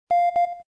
Timer.wav